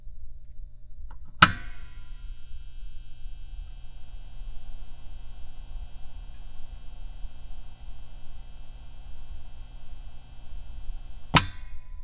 Deliverance II 60 Mechanical Buzz
My Deliverance II 60 makes a mechanical buzz when I take it off standby.
The amp also just has a general buzz level that’s significantly higher than my other amps plugged into the same outlet.